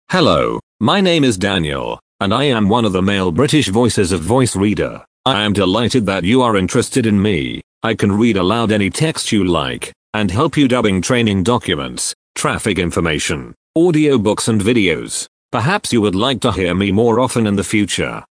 Voice Reader Home 22 English (British) - Male voice [Daniel]
Voice Reader Home 22 ist die Sprachausgabe, mit verbesserten, verblüffend natürlich klingenden Stimmen für private Anwender.